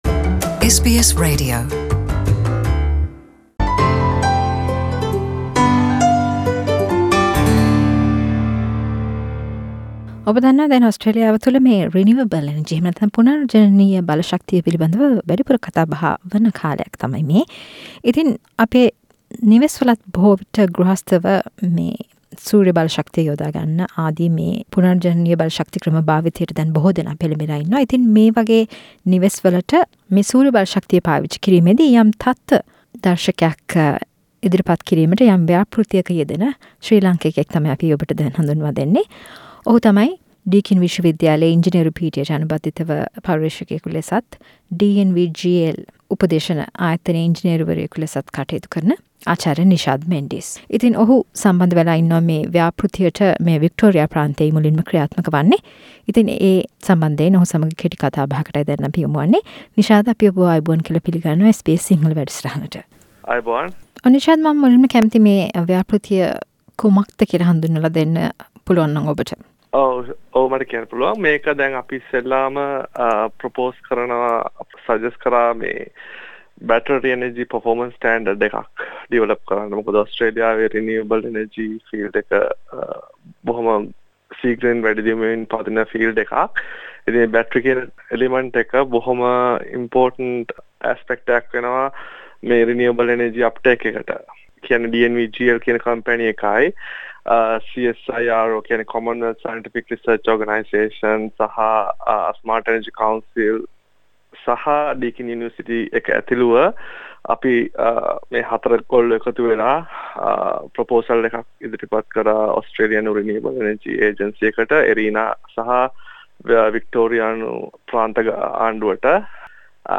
SBS සිංහල වැඩසටහන සිදුකල සාකච්චාවක්